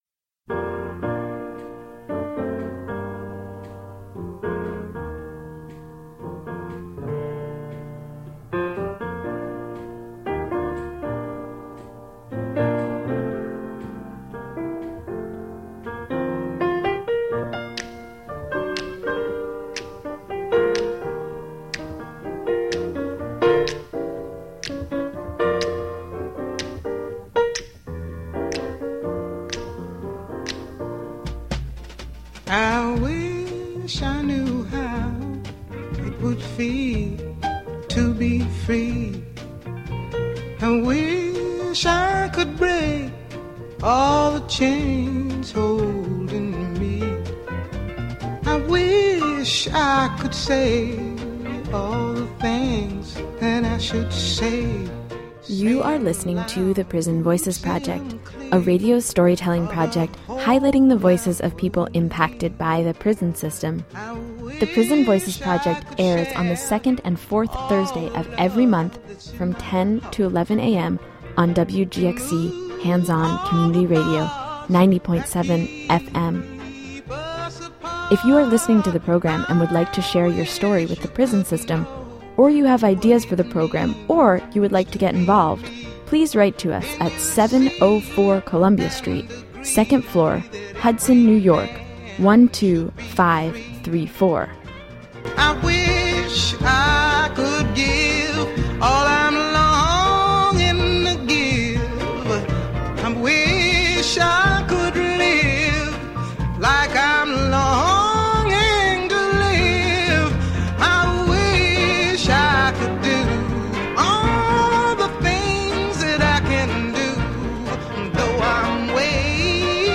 The program takes a critical look at criminal justice reforms, particularly in the context of recent events in Ferguson, Missouri and Staten Island. It also features the poem Riot Act, April 29, 1992 by Ai and read by poet Reginald Dwayne Betts.